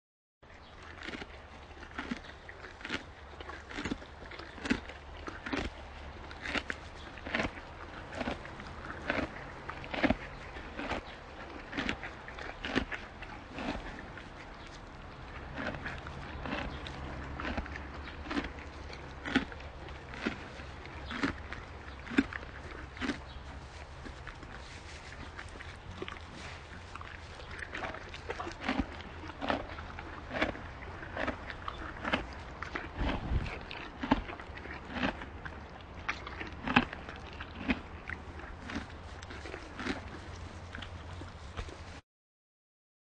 Звуки чистые и очень милые.
Категория: Звуки лошади.
Лошадь жует траву, звук -43 секунды